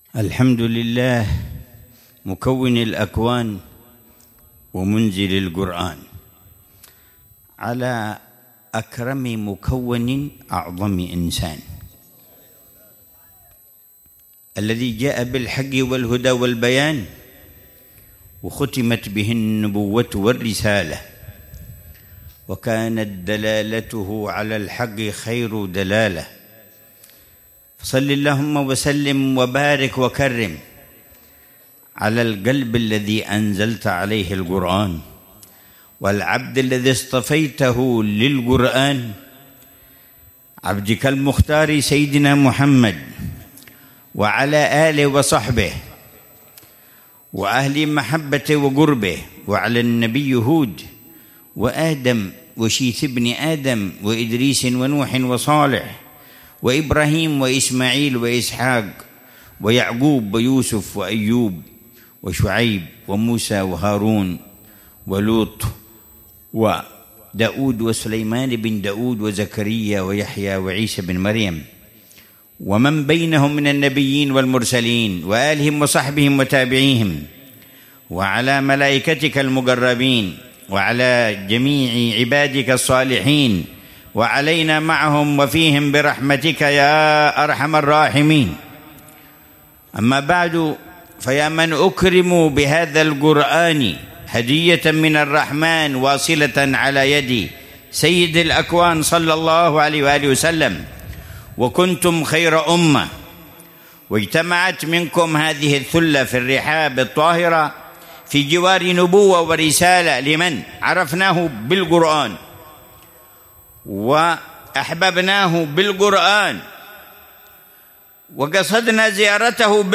محاضرة العلامة الحبيب عمر بن محمد بن حفيظ في المجلس الثاني من مجالس الدعوة إلى الله في شعب النبي هود عليه السلام، ضمن محاور (تقويم الإيمان وتقويم السلوك) ، ليلة الخميس 7 شعبان 1446هـ بعنوان: